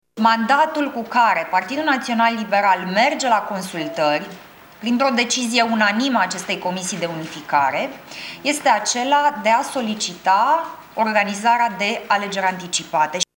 Liberalii merg la consultări cu propunerea de organizare a alegerilor anticipate, a afirmat, în urmă cu puțin timp, copreședintele PNL, Alina Gorghiu: